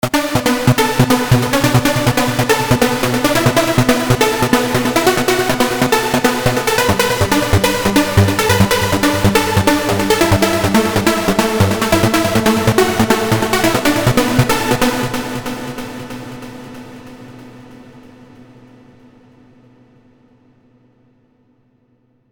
SR_Trance_Lead__open_filter_.mp3 SR_Trance_Lead__open_filter_.mp3 698,4 KB · Просмотры: 146 SR_Trance_Lead__open_filter_.rar SR_Trance_Lead__open_filter_.rar 17,9 KB · Просмотры: 104